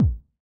INT Kick.wav